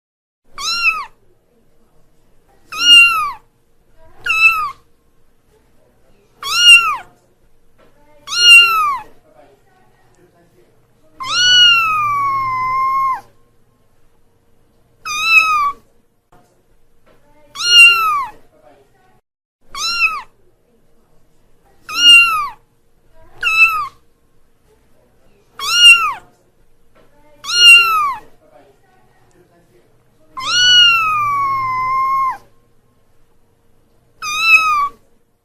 صوت القطط
يطلق على صوت القطة لفظ واسم : مواء
• وتصدر القطة هذا الصوت لغرض التواصل مع بعضها البعض مثلنا نحن البشر، ويمكن أن تعبّر القطه من خلاله على حالتها ومزاجها من القلق والغضب والجوع، ويختلف صوتها من نوع الى نوع أخر ويختلف أيضا في نغماته وحدّته.
صوت-القطة.mp3